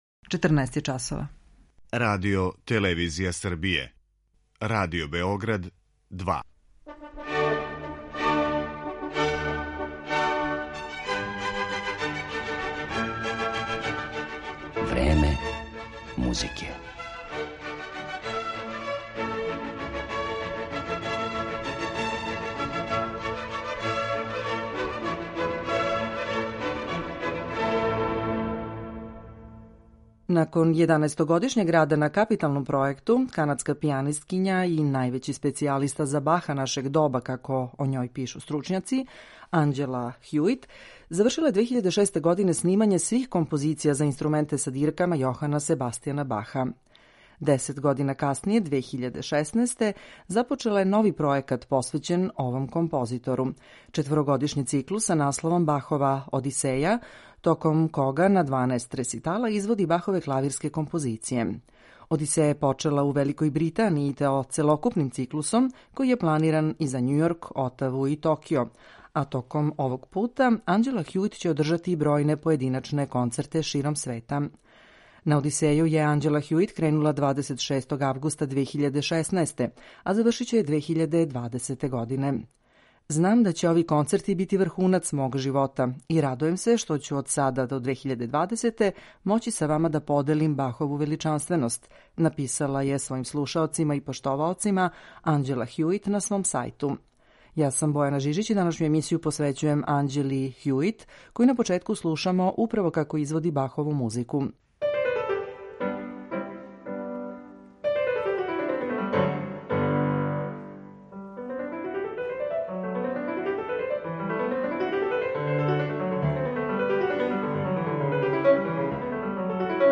Поред Бахових дела, ова ова врхунска уметница специфичног сензибилитета и укуса, изводиће и композиције Жана Филипа Рамоа, Лудвига ван Бетовена и Мориса Равела.